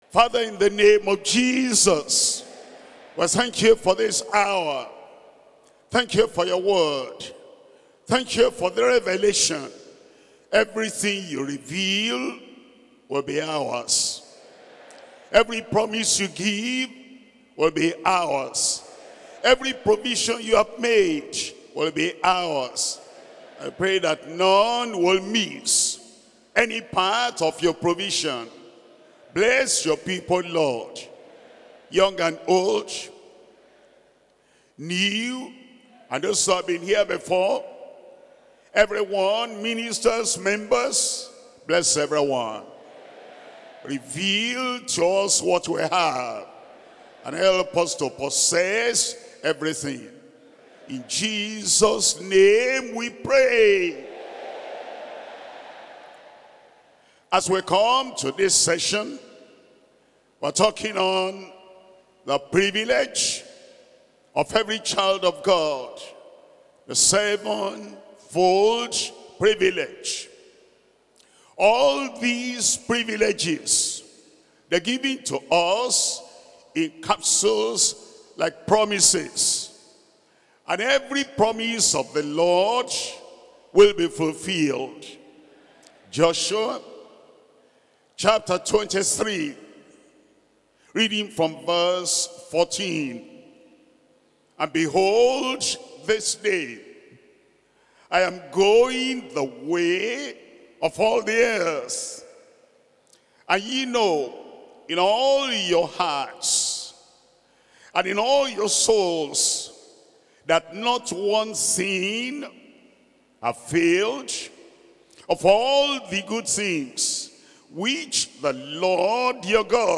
Sermons - Deeper Christian Life Ministry
2025 Global December Retreat